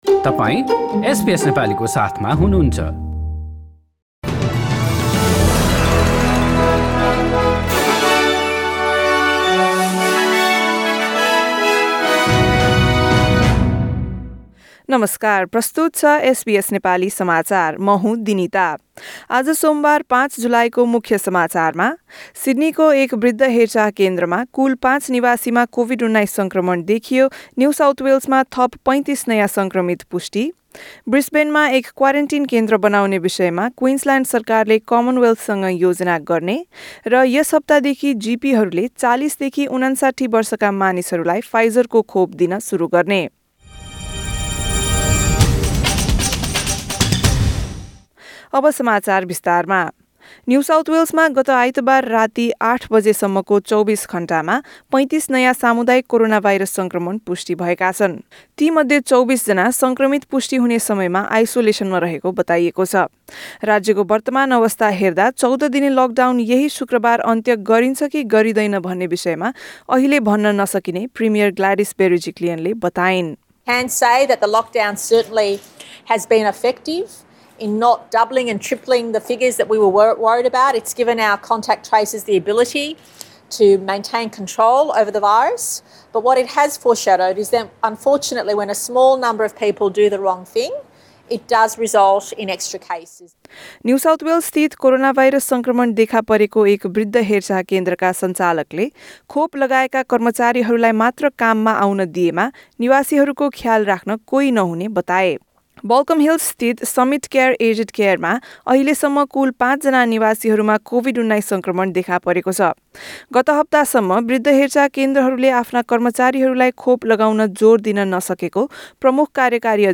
एसबीएस नेपाली अस्ट्रेलिया समाचार: सोमबार ५ जुलाई २०२१